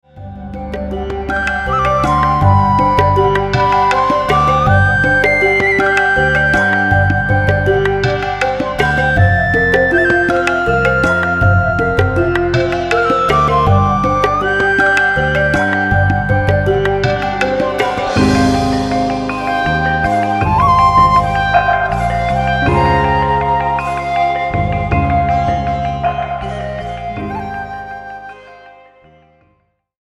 コンピレーションのBGM集です。
美容室のコンセプトに沿った、安らぎの得られる楽曲が